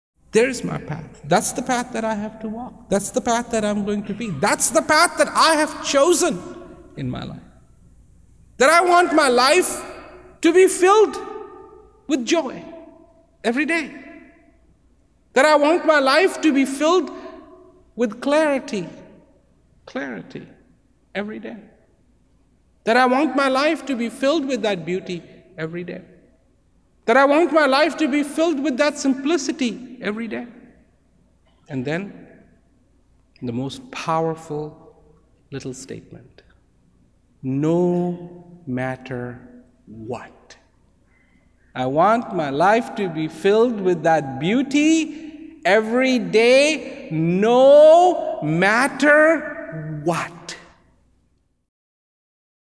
Transcripts of Rawat's speeches cannot do justice to Rawat's astonishing delivery, they have to be heard to be appreciated. Mp3 copies of these excerpts are recorded at high quality (256Kbps) to ensure no whispered nuance or frenzied climax is missed.